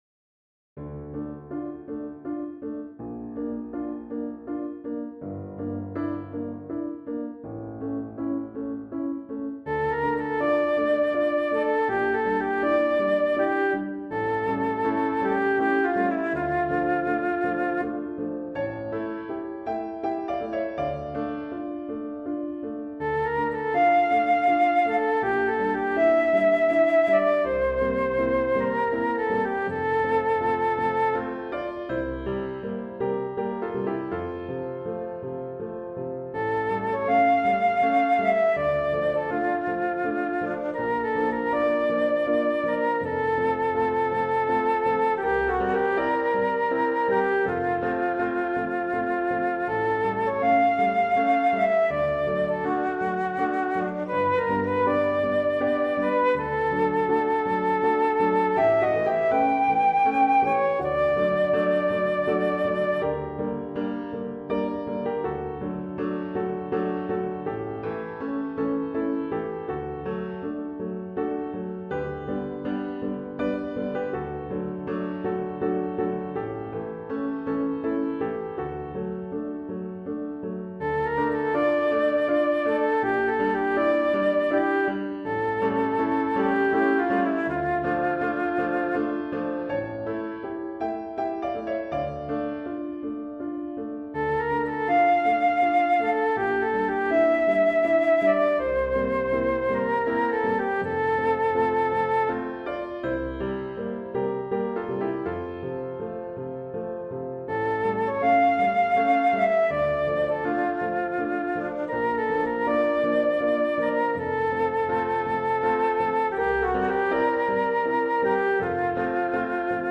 arranged for Flute (or other) & Piano